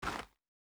Shoe Step Gravel Hard B.wav